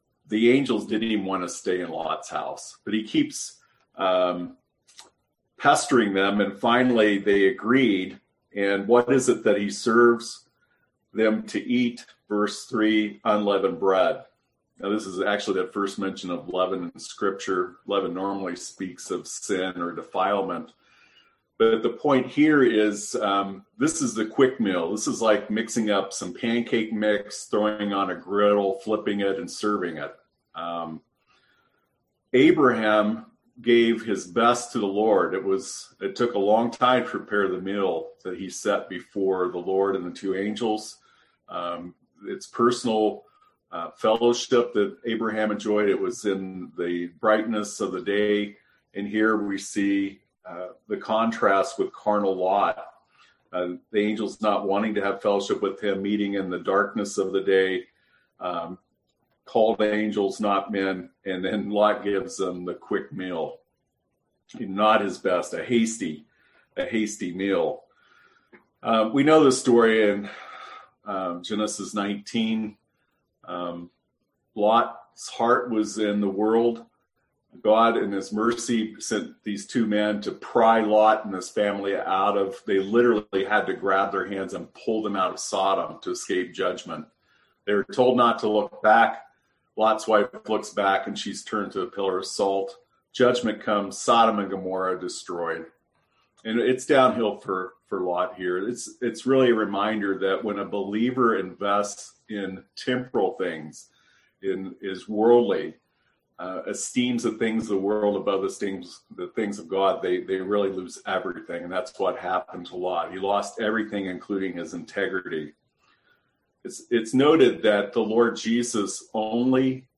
Service Type: Sunday AM Topics: Carnal , Worldliness